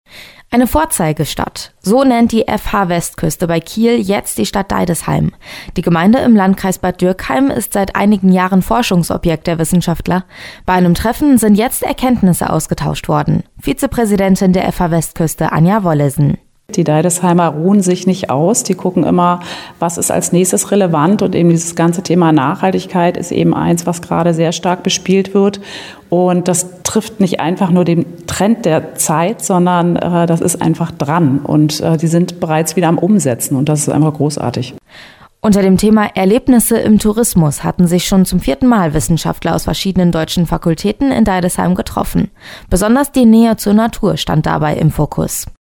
Radiobericht auf Antenne Pfalz